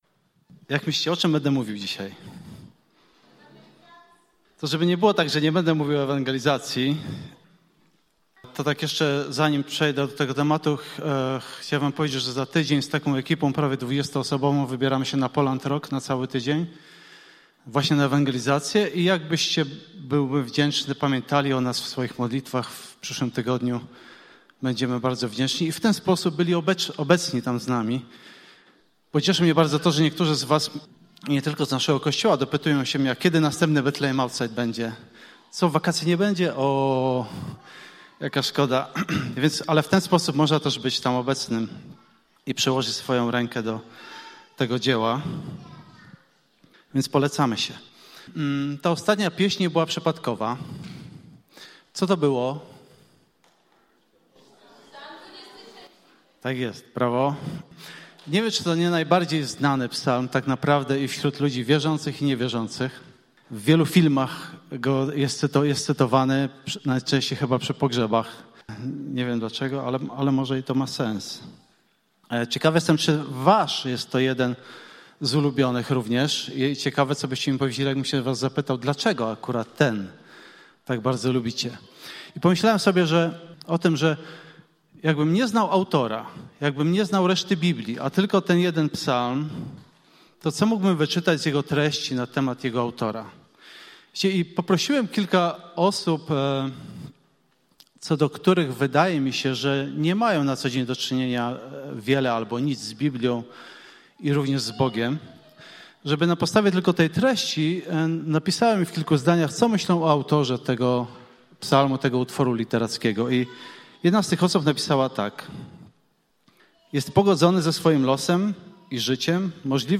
Refleksja po kazaniu: